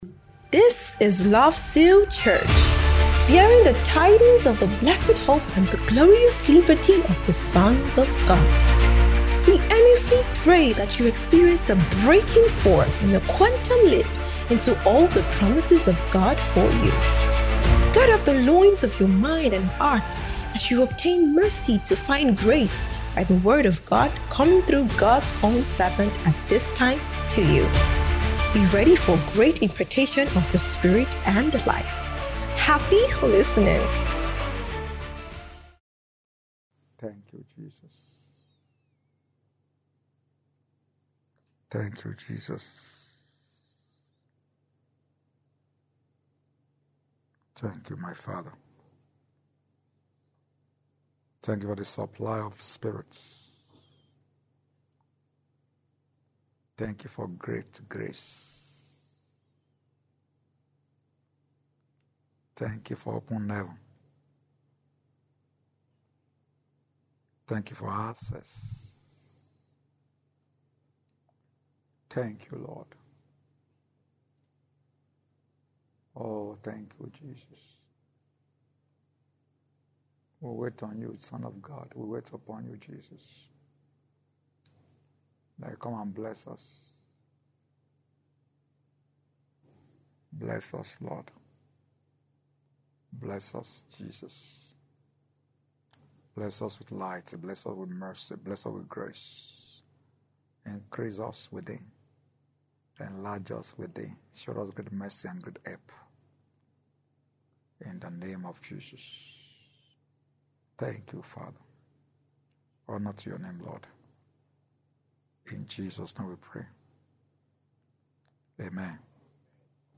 SOBT - SPECIAL MIDWEEK TEACHING SERIES